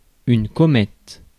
Ääntäminen
US : IPA : [ˈkɒ.ət] Tuntematon aksentti: IPA : /ˈkɒ.mət/